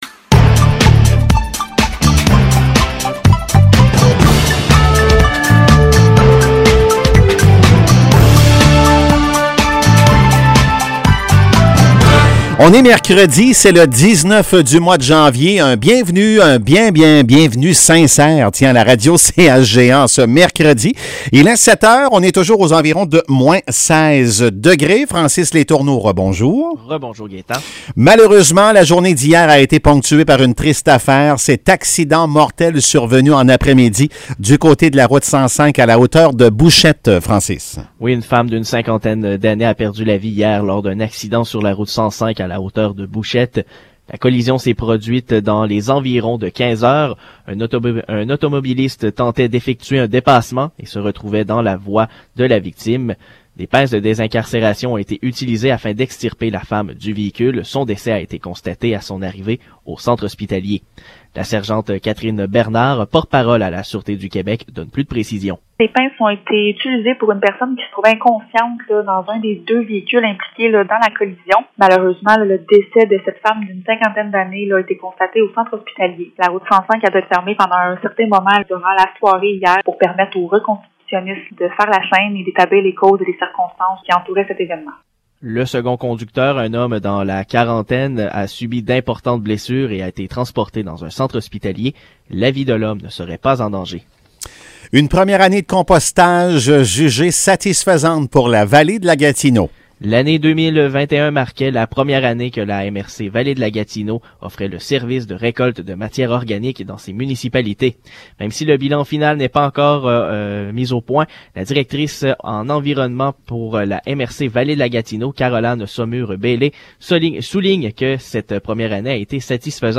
Nouvelles locales - 19 janvier 2022 - 7 h